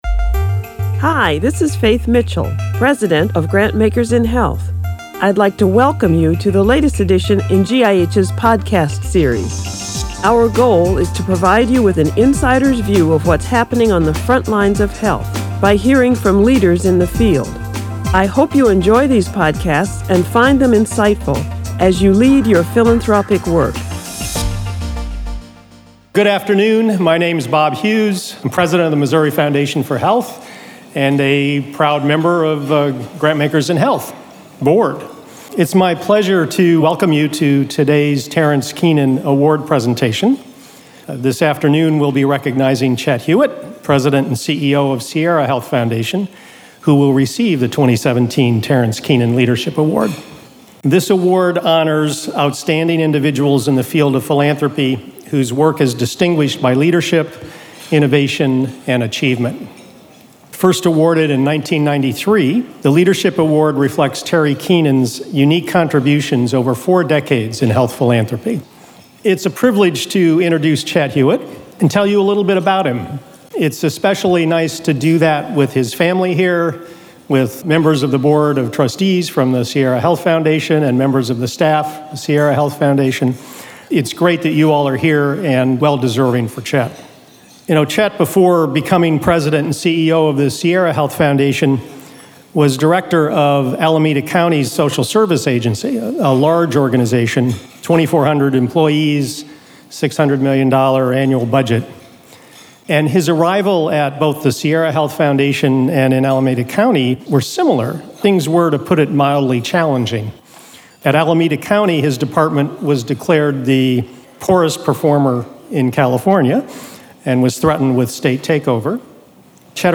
2017 Terrance Keenan Award Speech